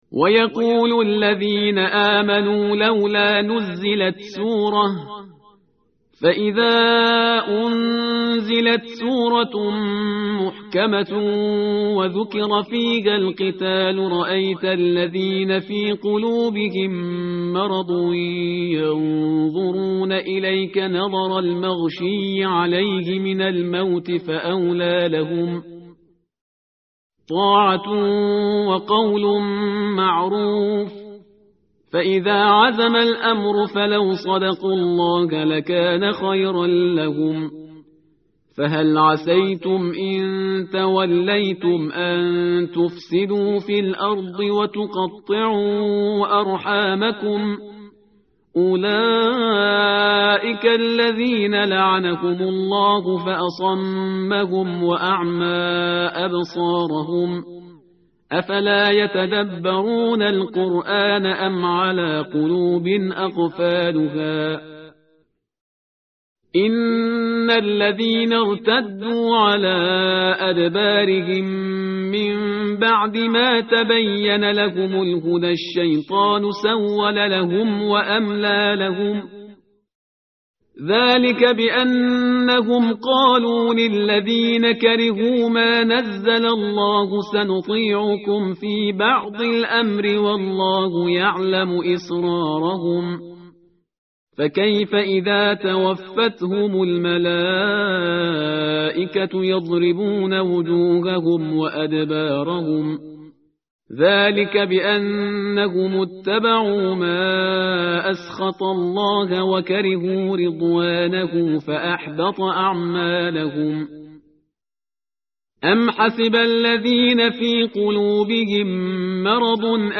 متن قرآن همراه باتلاوت قرآن و ترجمه
tartil_parhizgar_page_509.mp3